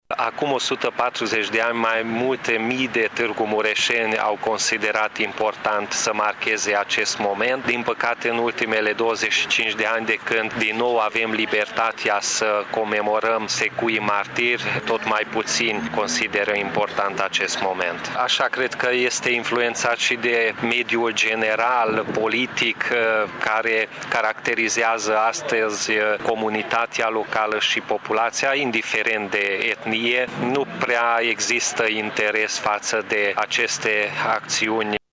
Declaraţia aparţine viceprimarului Municipiului Tîrgu-Mureş, Peti Andras, care participă la această oră la manifestările comemorative de la Monumentul Secuilor Martiri din Tîrgu-Mureş.
După Revoluţie, însă, tot mai puţini maghiari au dat importanţă acestor manifestări spune viceprimarul din Tîrgu-Mureş, Peti Andras: